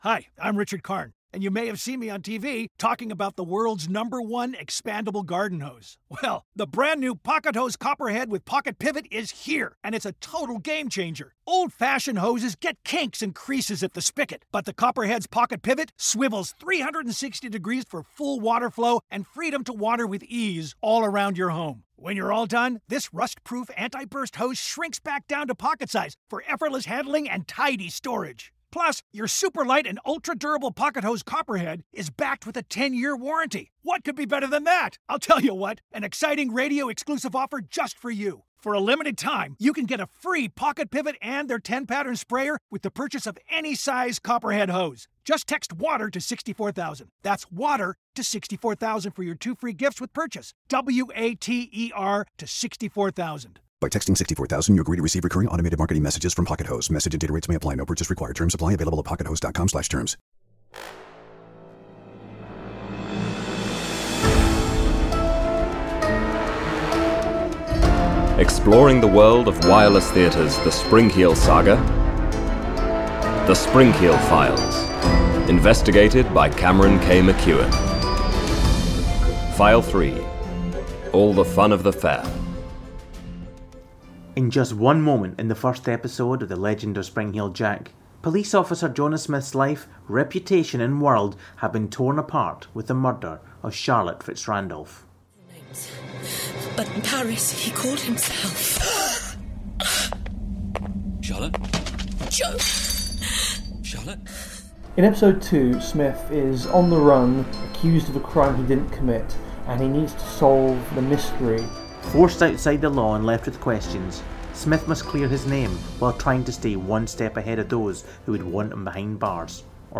With exclusive interviews, commentary and clips, we explore the world of The Carnival of Horrors, the second episode of the second series of The Legend of Springheel’d Jack.
With contributions from the cast